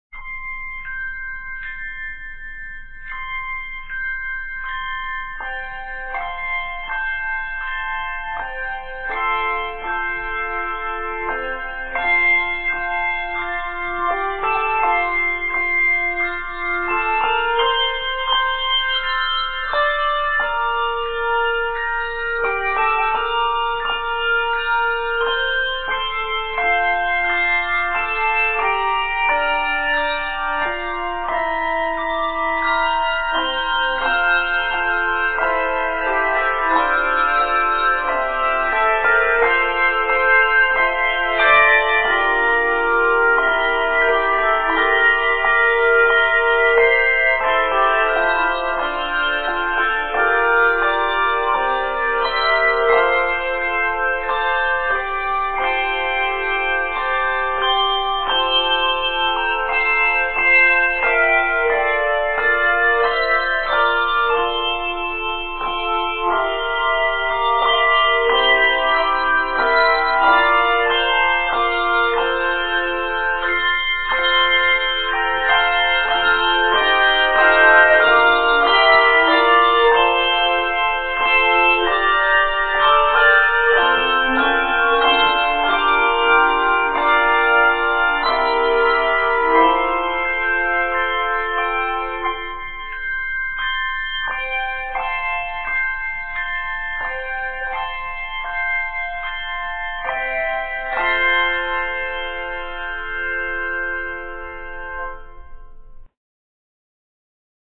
level one arrangement
Mostly chordal, it is scored in F Major and is 42 measures.